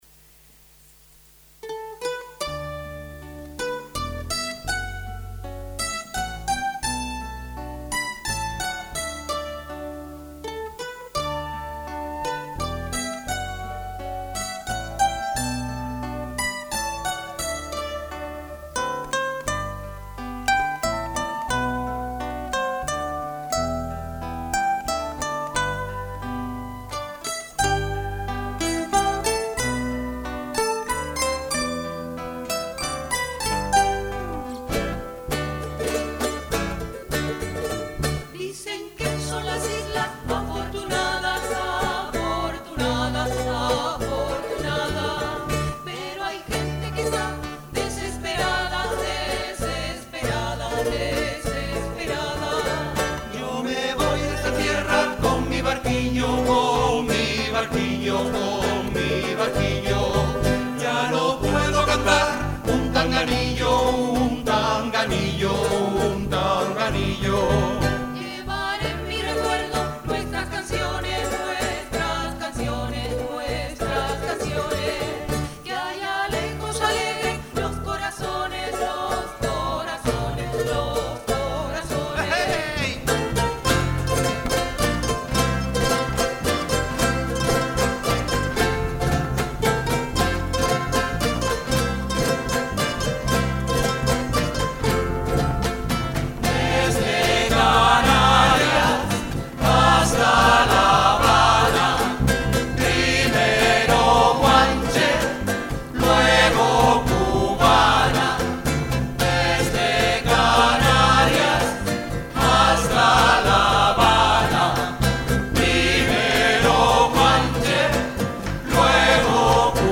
El jueves 16 de mayo, de 19:00 a 20:30 horas, se celebró en el salón de actos de la Facultad de Formación del Profesorado, con la participación del grupo "LasPalmeños ULPGC", siendo además su presentación oficial en la universidad con el nuevo nombre, y con nuevos temas.